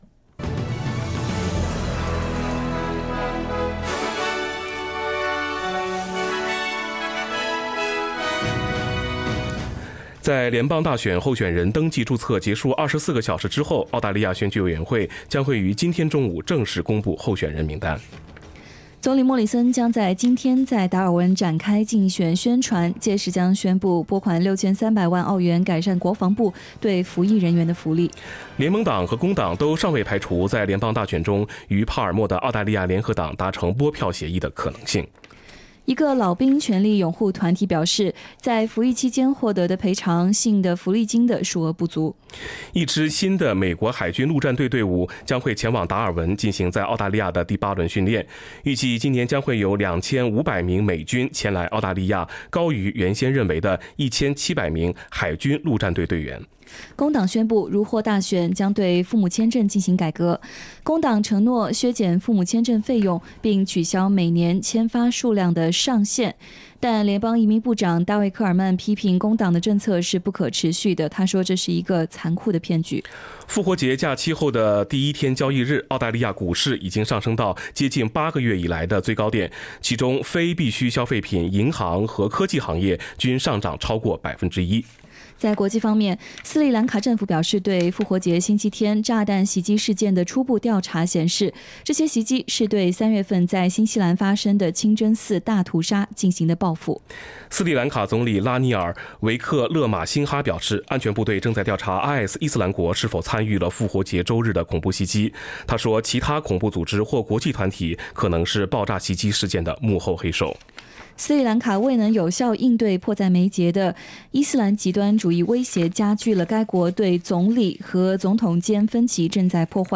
SBS Chinese Morning News Source: Shutterstock
morning_news_april_24.mp3